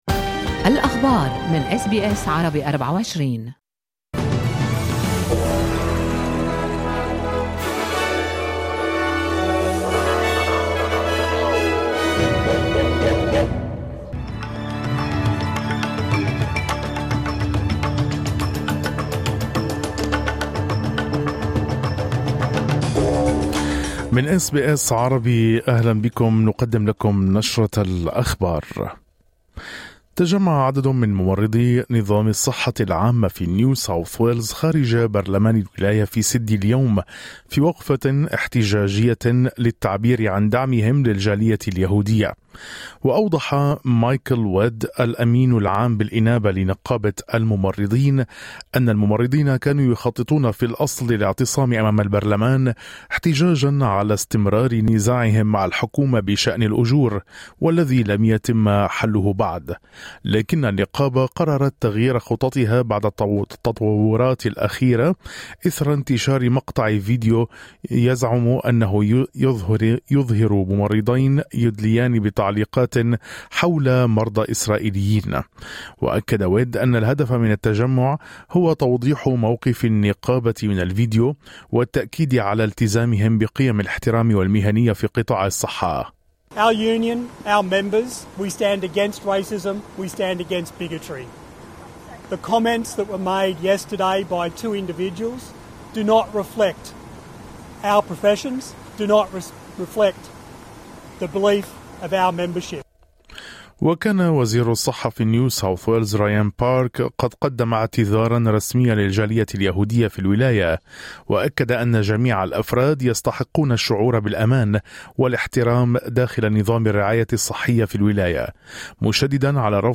نشرة أخبار الظهيرة 13/02/2025